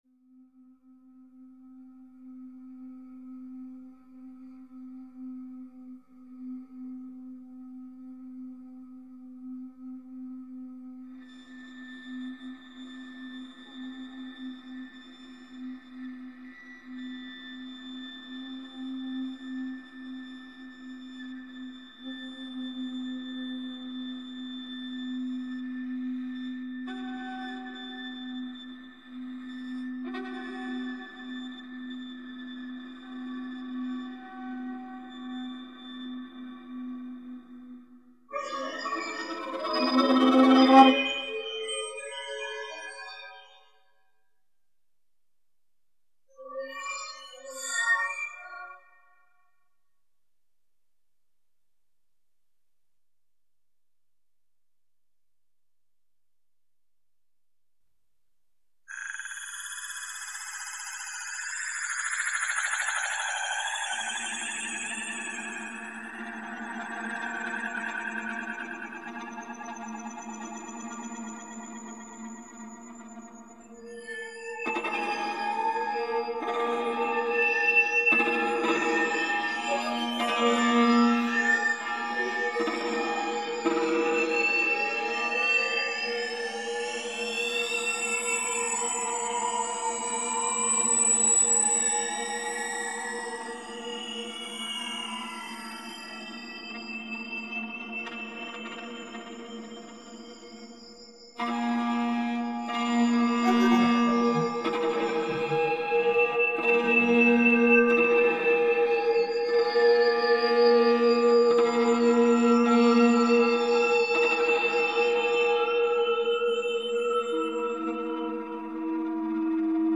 Oda para flauta dulce tenor, viola y electrónica